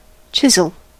Ääntäminen
Ääntäminen US : IPA : [ˈtʃɪz.əl]